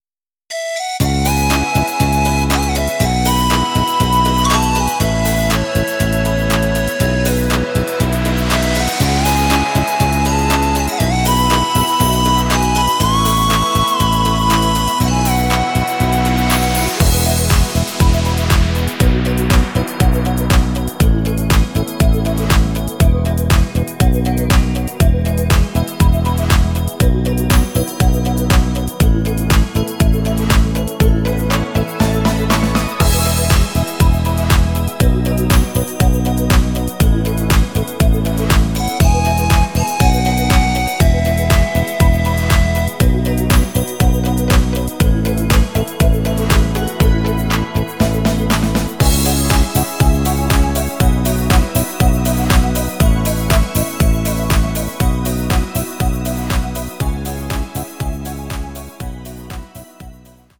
new Mix Dance Version